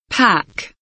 pack kelimesinin anlamı, resimli anlatımı ve sesli okunuşu